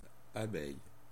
• ÄäntäminenFrance (Paris):
• IPA: [y.n‿a.bɛj de.z‿a.bɛj]